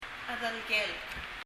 パラオ語 PALAUAN language study notes « white 白い red 赤い » black 黒い chedelekelek [! ɛðələk(g)ələk] 英） black 日） 黒い Leave a Reply 返信をキャンセルする。